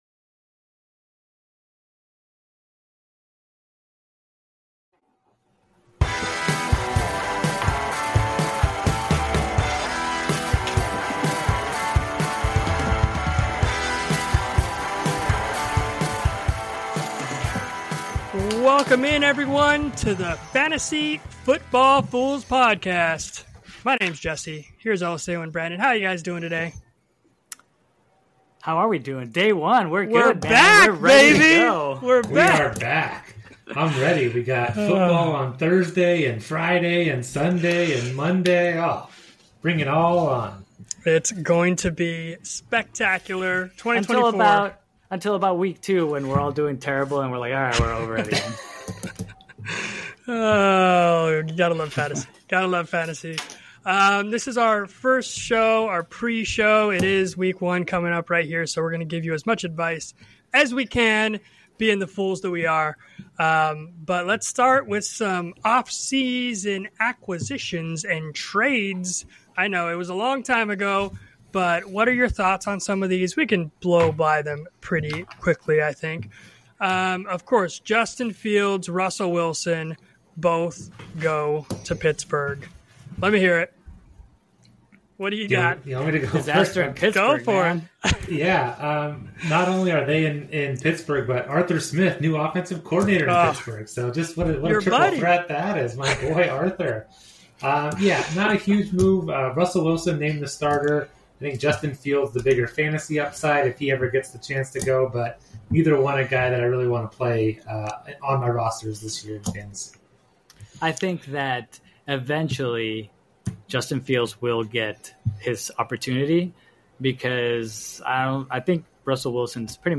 In this part of the conversation, the hosts discuss sleeper picks and busts for the upcoming NFL season.